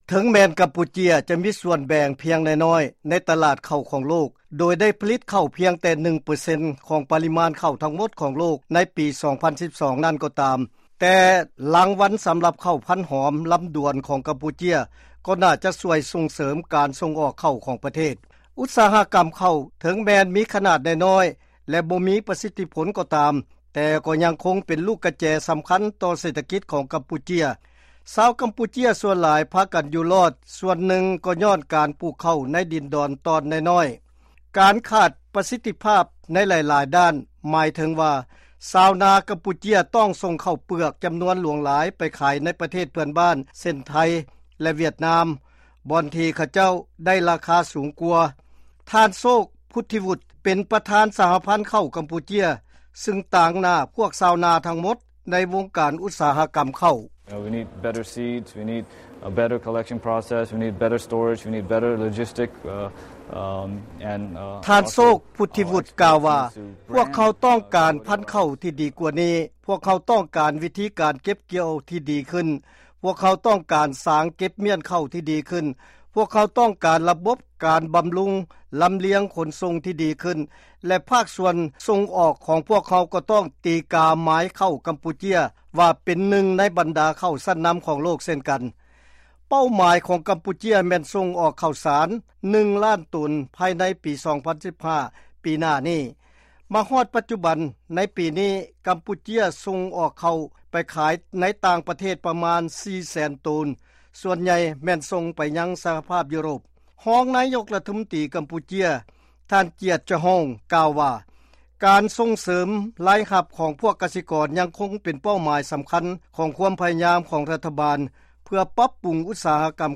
ຟັງລາຍງານກ່ຽວກັບເຂົ້າຂອງກຳປູເຈຍ